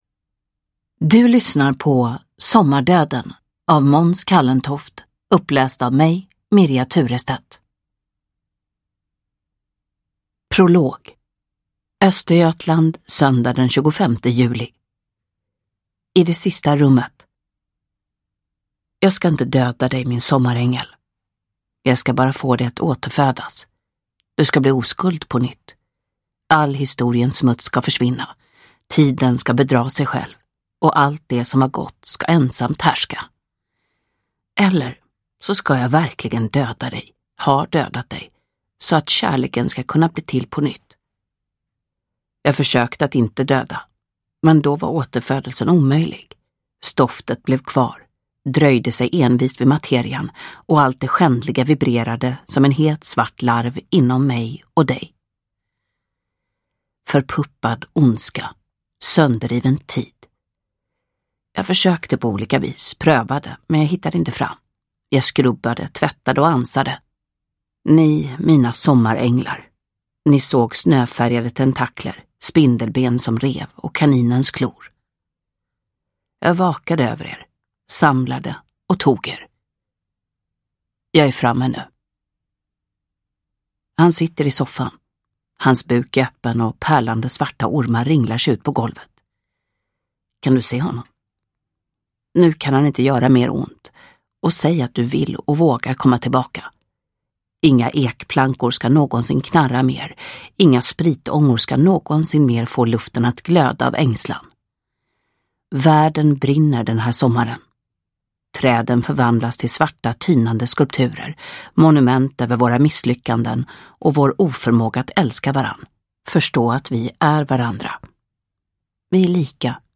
Uppläsare: Mirja Turestedt
Ljudbok